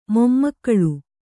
♪ mommakkaḷu